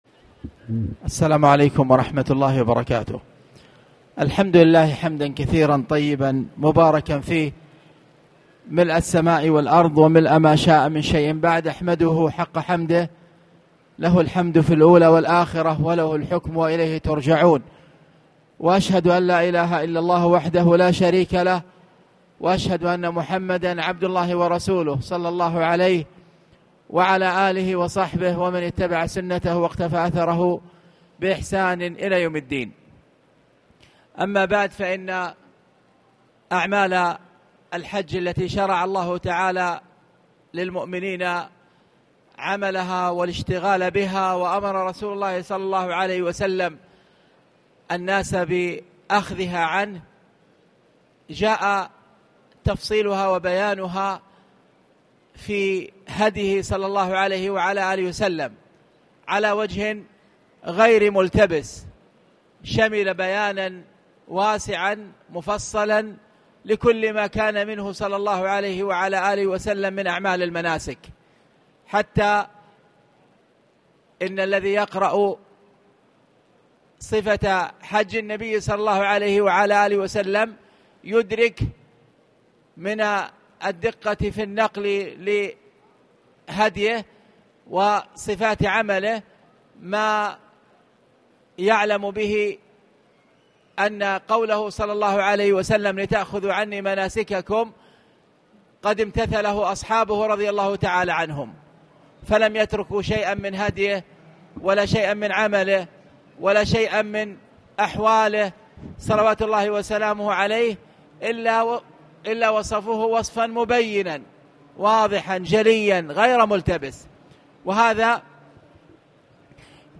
تاريخ النشر ٢٩ شوال ١٤٣٨ هـ المكان: المسجد الحرام الشيخ